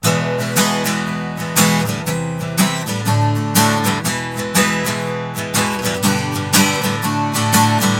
描述：Takamine的麦克风，距离音孔10"。完全是原始循环。
Tag: 120 bpm Folk Loops Guitar Acoustic Loops 1.35 MB wav Key : E